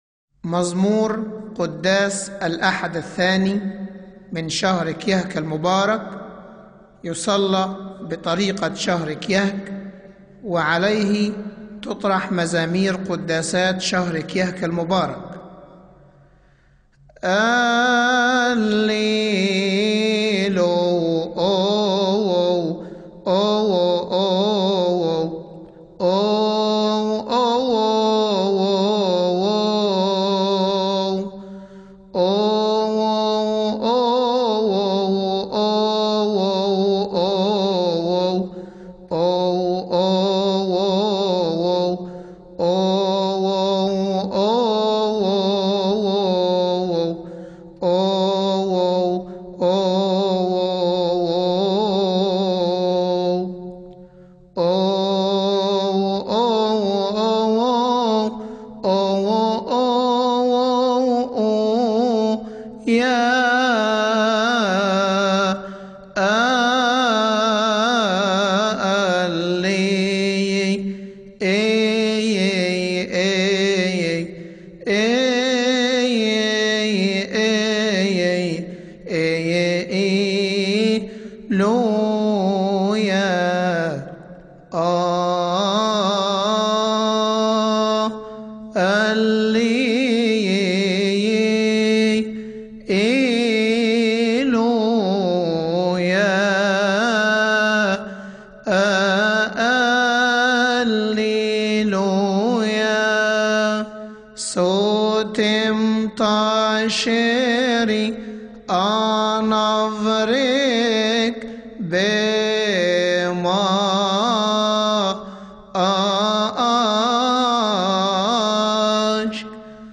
مزمور الأحد الثاني من شهر كيهك